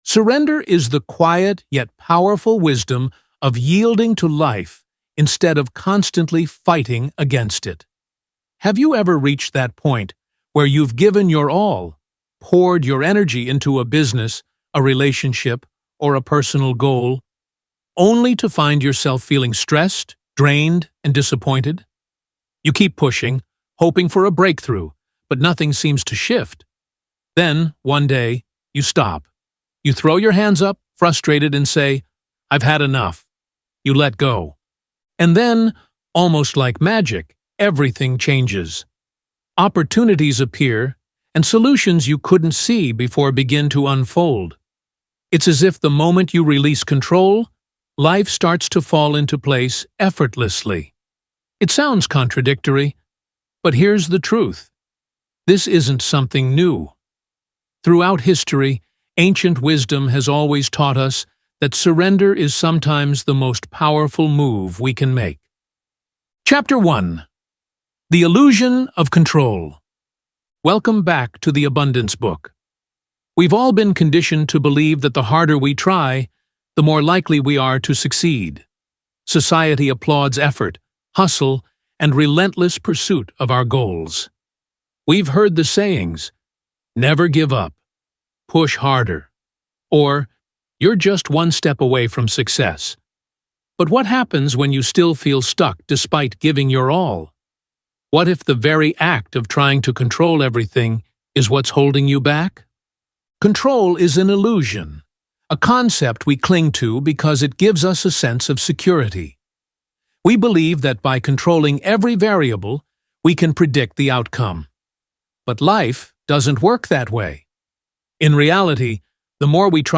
Shadows in the Attic: Secrets Hidden Beyond the Darkness (Audiobook)